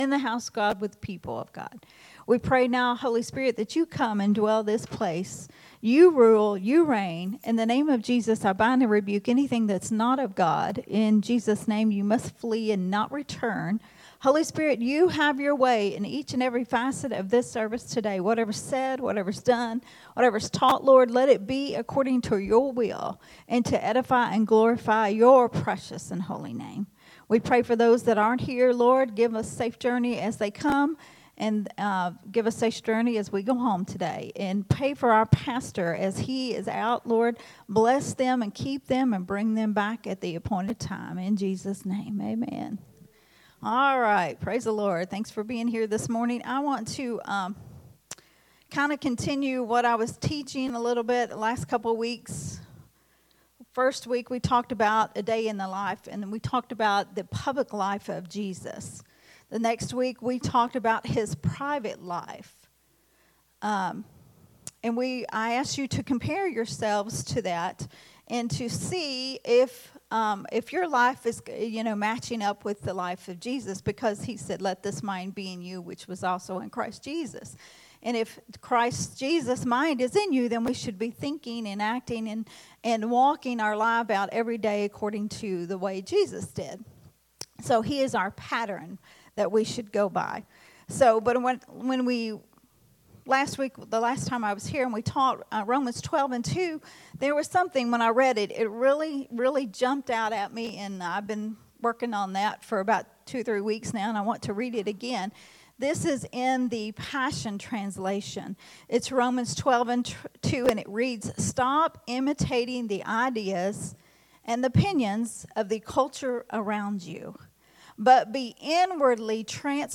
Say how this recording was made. recorded at Unity Worship Center on Sunday, May 19th, 2024.